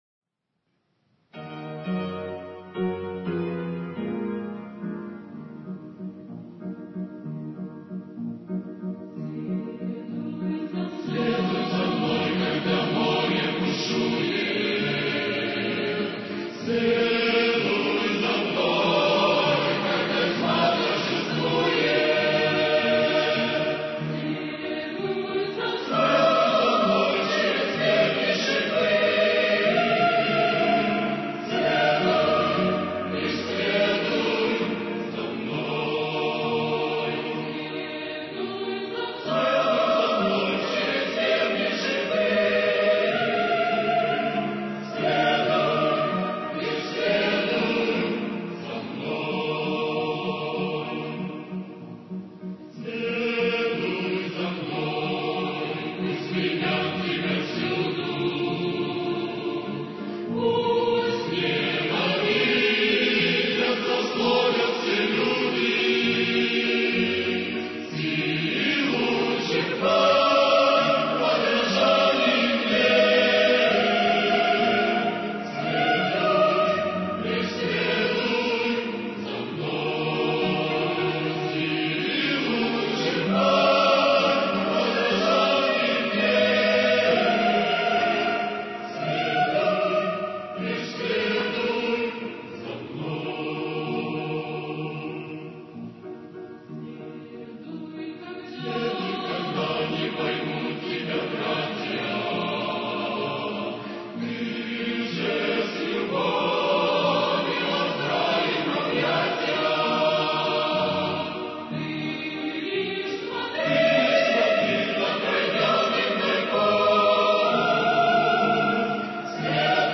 Хор